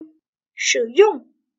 shǐyòng - sử dung Sử dụng